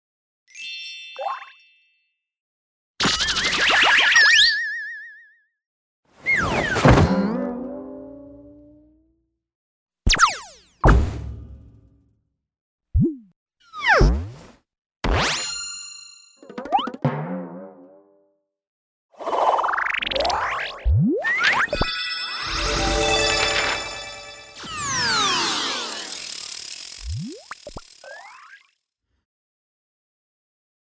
游戏音效
[卡通类]
标签 卡通 角色叫声设计 儿童 动作
无论是角色滑倒时的弹簧声、头顶冒金星的叮当响，还是表情突变时的滑稽变调、身体拉伸扭曲的弹性音效，乃至幻想情境中的超现实声响，均属于卡通音效的范畴。
以经典动画为例，当角色瞬间移动时伴随的“嗖”声、被重物砸中时的闷锣声，或晕头转向时耳鸣般的螺旋音效，虽非现实存在，却通过巧妙的声音拼贴与拟音设计，精准传递动作节奏与情绪状态。